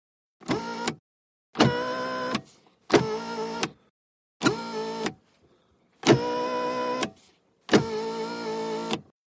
VEHICLES - AUTO ACCESSORIES: Electric window, close in jerks.